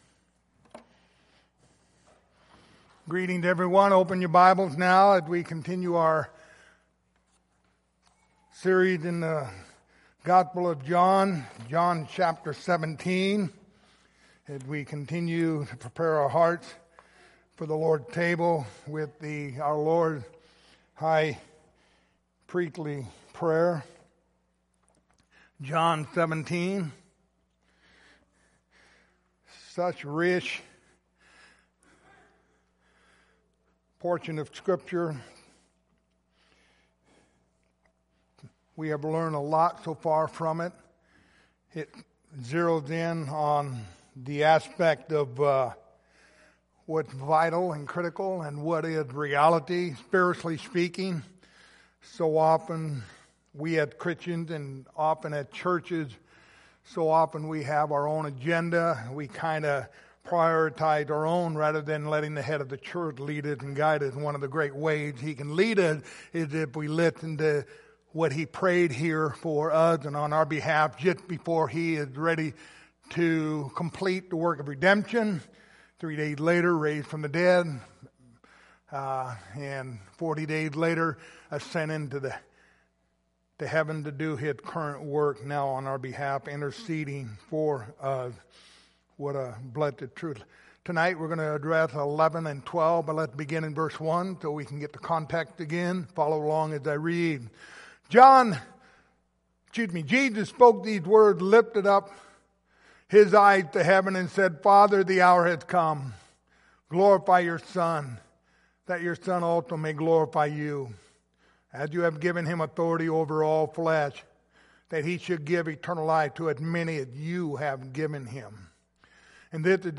Passage: John 17:11-12 Service Type: Lord's Supper